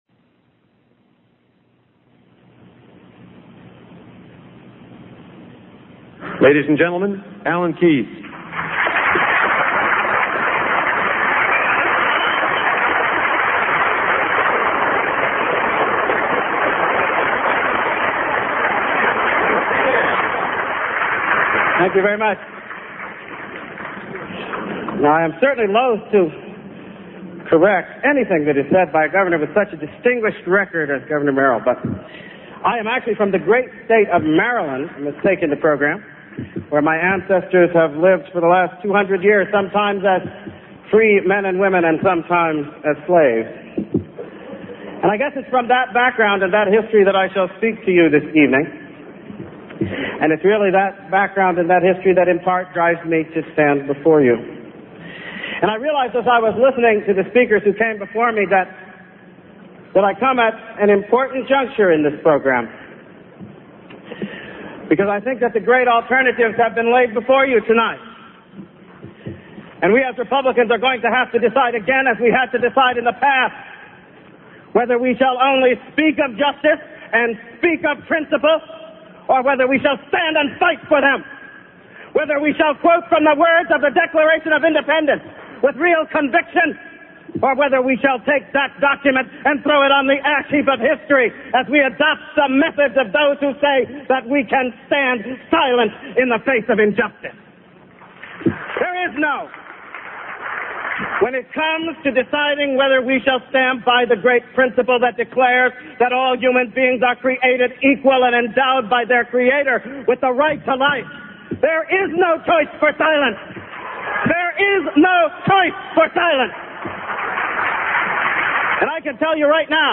MP3 audio Speech Republican Candidate Event in New Hampshire Alan Keyes February 19, 1995 The following text was taken from a Focus on the Family broadcast that was aired February 22, 1995.